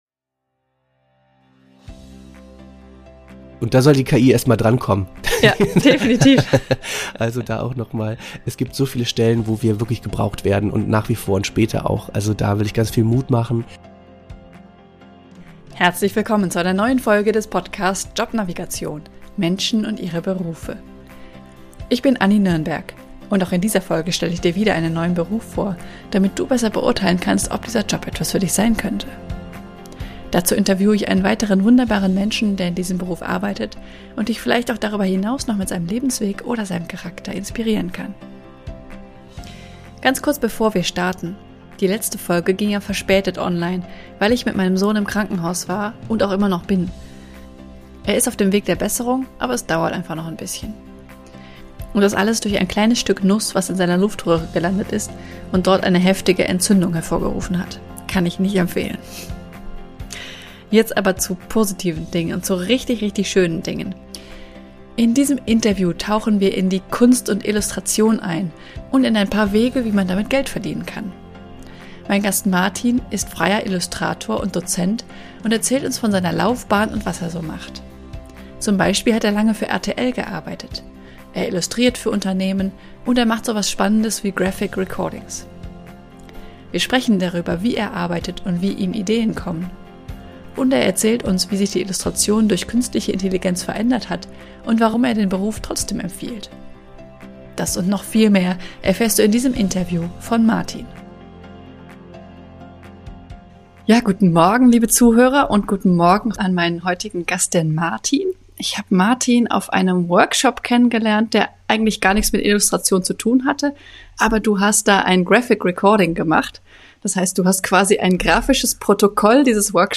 In diesem Interview tauchen wir in die Kunst und Illustration ein - und ein paar Wege, wie man damit Geld verdienen kann.